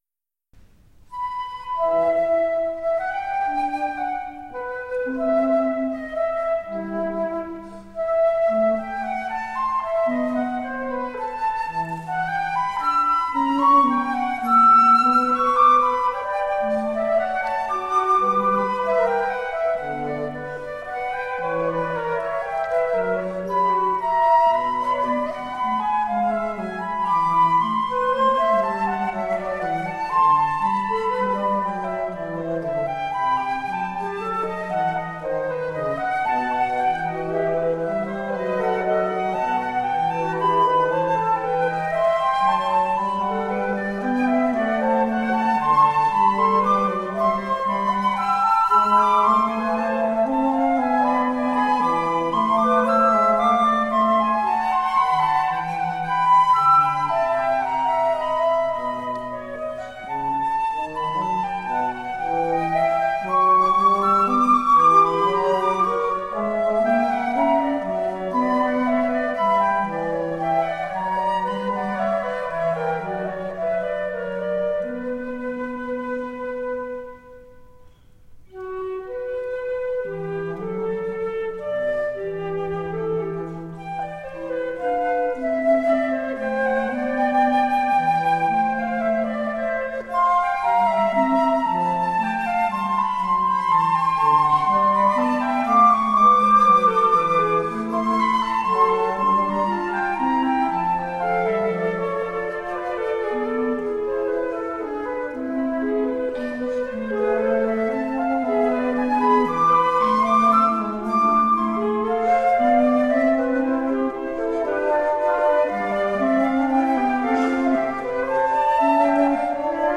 Flautists with finesse, intelligence and grooves.
flute ensemble
recorded live in Hakodate City Art Hall on 22 March 1999
Classical, Baroque, Impressionism, Instrumental
Flute